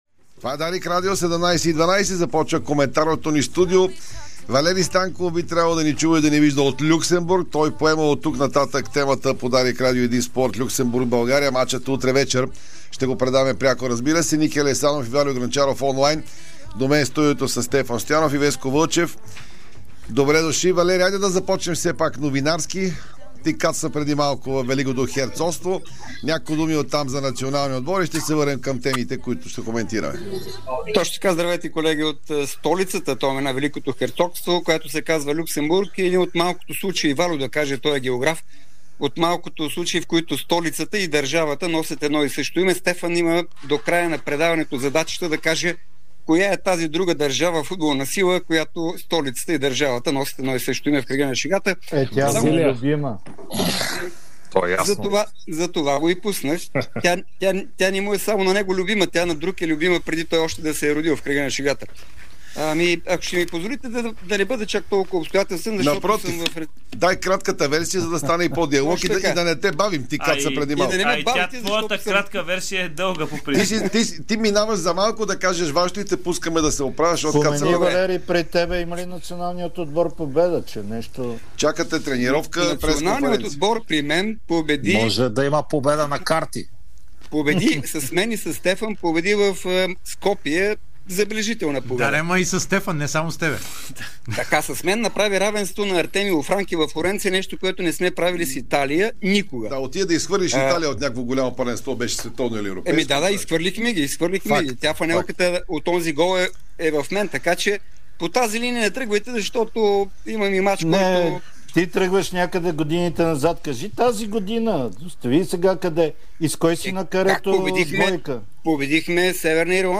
Коментарно студио след FootballNext и интервюто на Георги Иванов